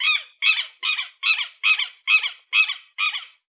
Kiebitz Ruf
Wegen seines markanten Rufs „kiewit“, der ihm seinen Namen gab.
Kiebitz-Ruf-2-Voegel-in-Europa.wav